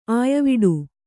♪ āyaviḍu